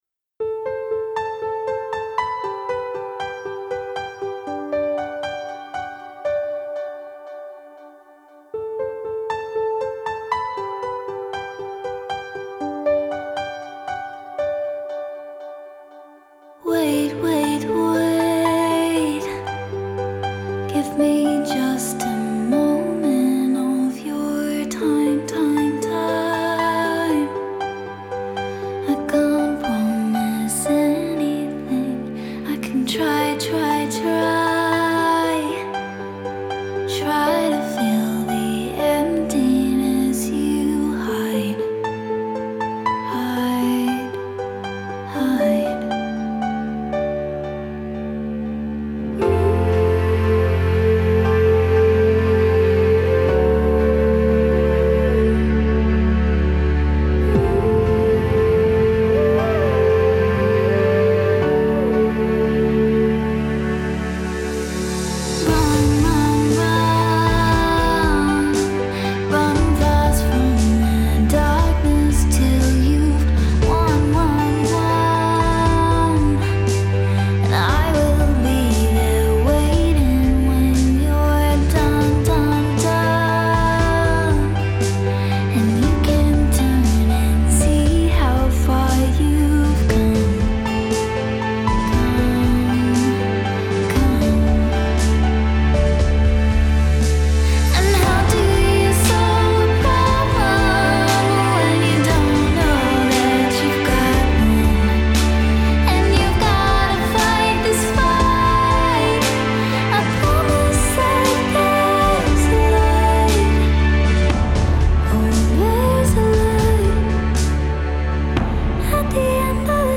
Genre: Electronic, Pop